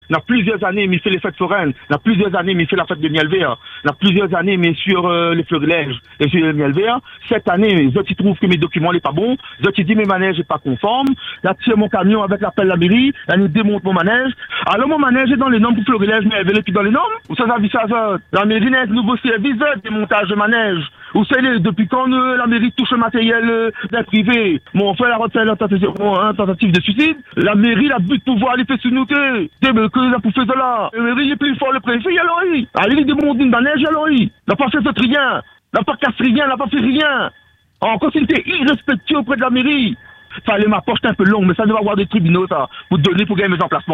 Juste avant de se barricader, le forain a accepté de livrer son témoignage à notre antenne, exprimant sa colère, son incompréhension et son profond désarroi.